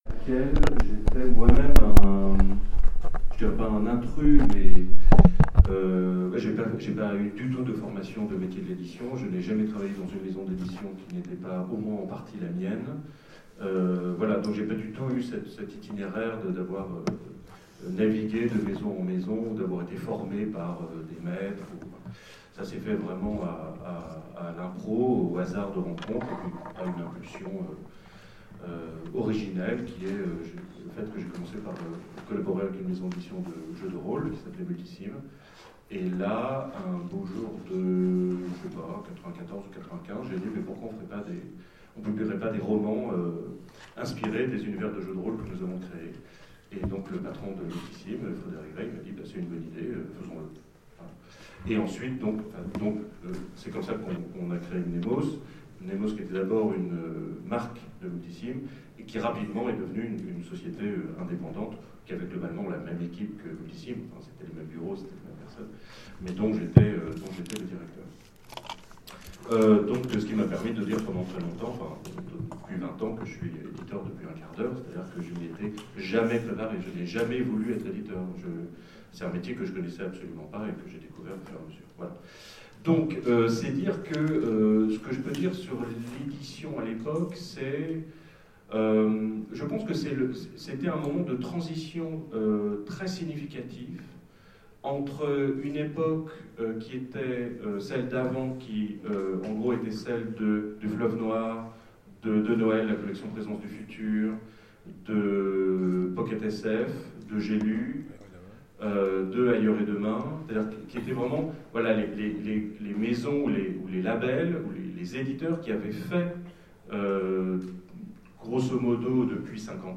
FMI 2016 : Conférence Les nouvelles problématiques du monde de l'édition